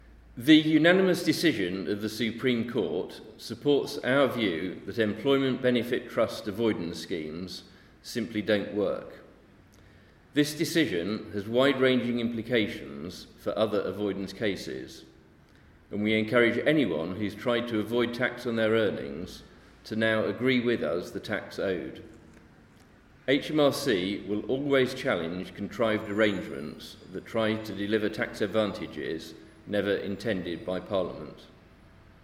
Statement from David Richardson, Director General of Customer Compliance, on the Supreme Court result with regards to Rangers Football Club on 5 July 2017.